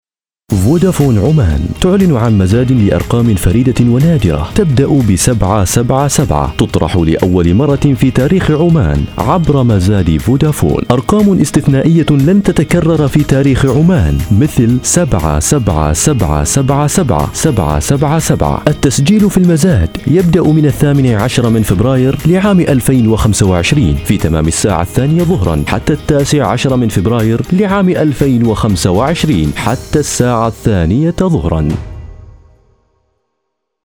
أصوات الذكور - هلا أف أم
استمع للصوت الإعلاني، ليكن ضمن مشروعك القادم.